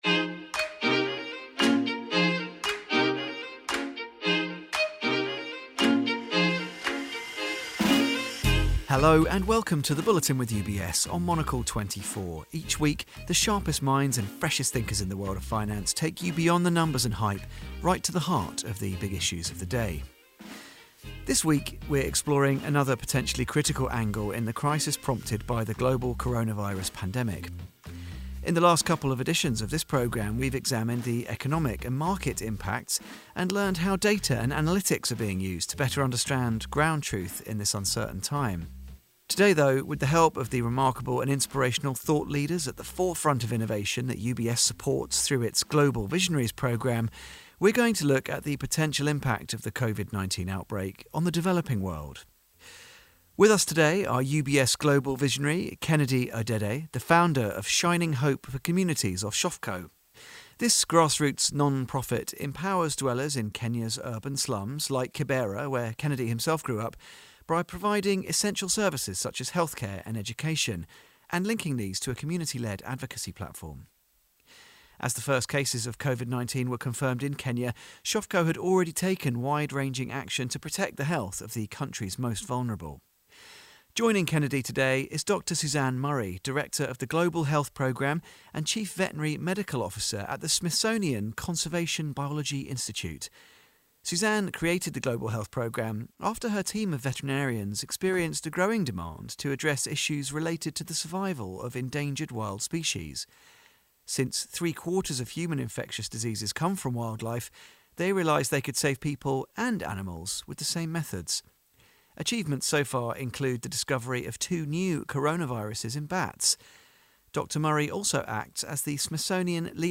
Two Global Visionaries discuss the pandemic